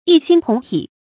一心同体 yī xīn tóng tǐ
一心同体发音
成语注音 ㄧ ㄒㄧㄣ ㄊㄨㄙˊ ㄊㄧˇ